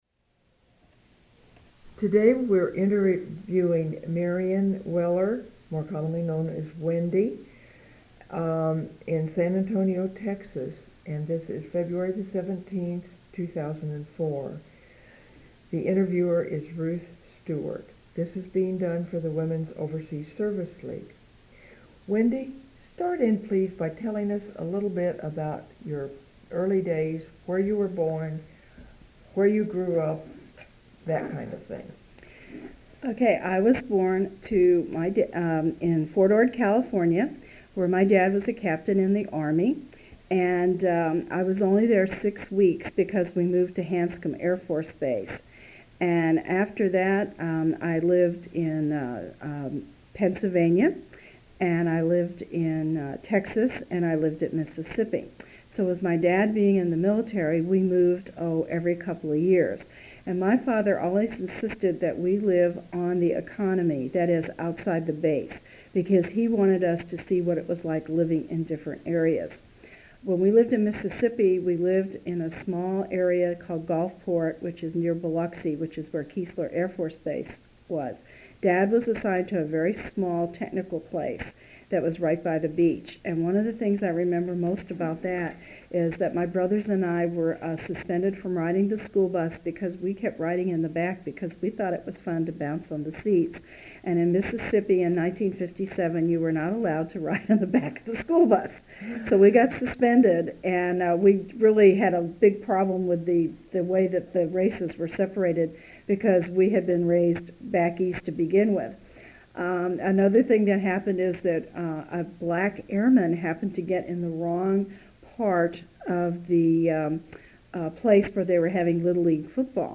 Sound recordings Interviews